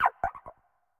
Cri de Nigirigon dans sa forme Affalée dans Pokémon Écarlate et Violet.
Cri_0978_Affalée_EV.ogg